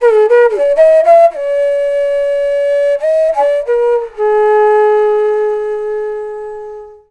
FLUTE-A11 -R.wav